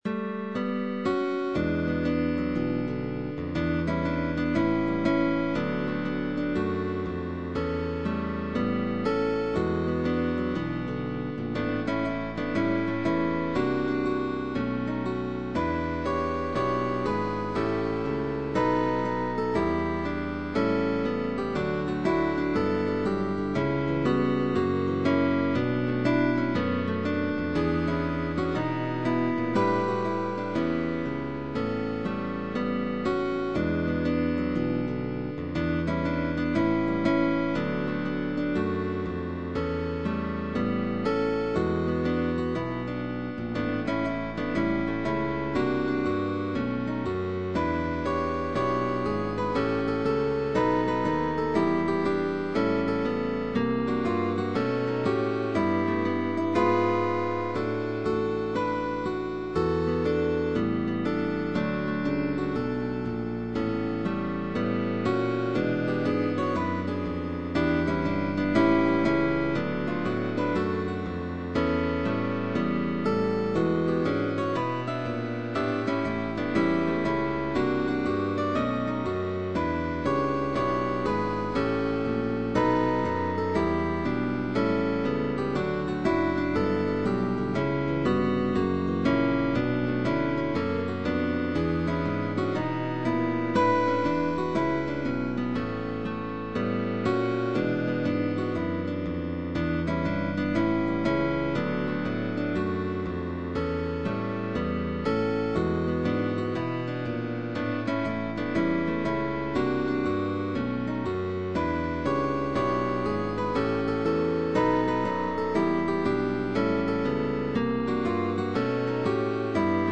GUITAR QUARTET
Arpeggios and two and three note chords.
Slurs. Dynamics Changes.